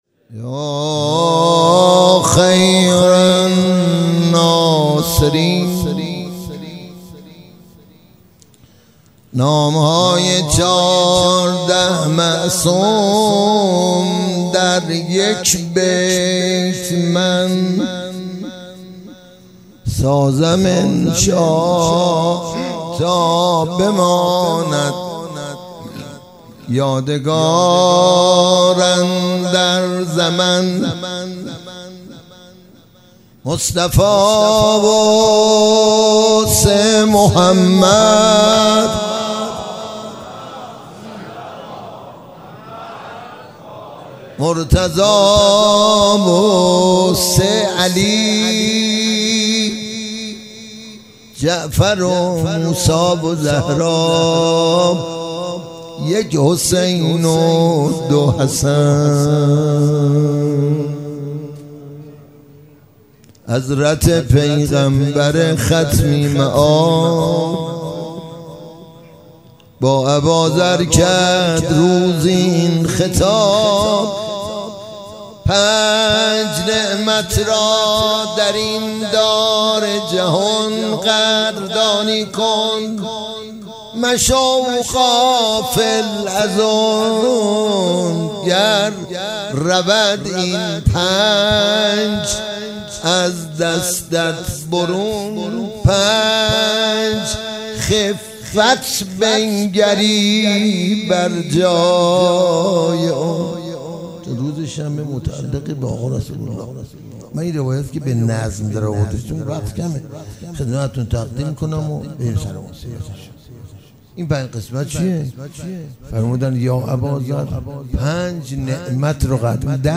پیش منبر
شهادت امام جواد (ع)